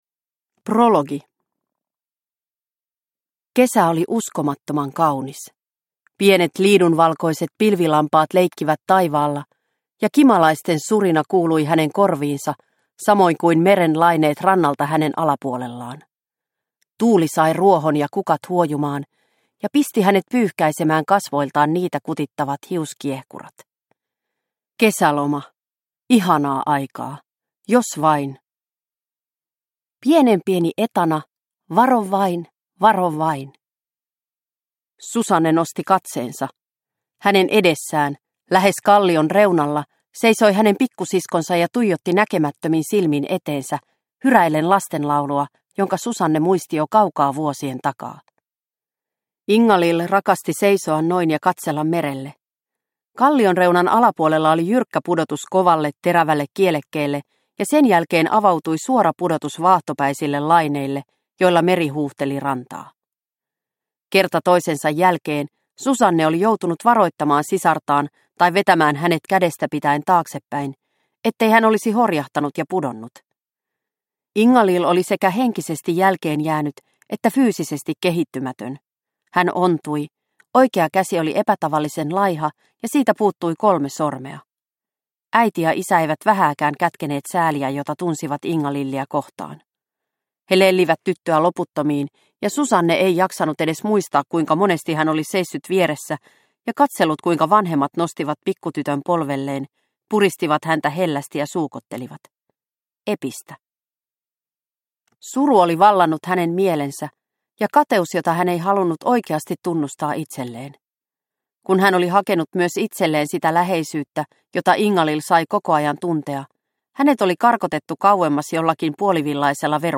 Tuomari – Ljudbok – Laddas ner